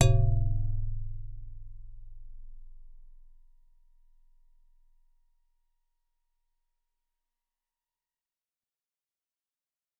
G_Musicbox-E0-f.wav